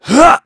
Clause_ice-Vox_Jump.wav